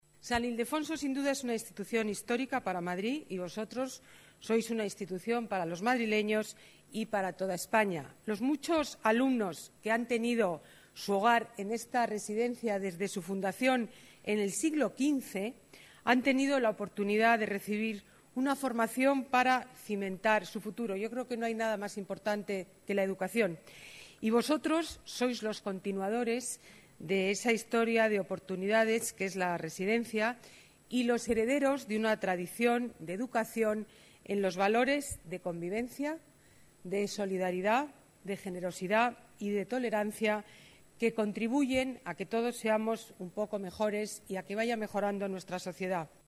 Nueva ventana:Declaraciones alcaldesa de Madrid, Ana Botella: entrega Becas Académicas San Ildefonso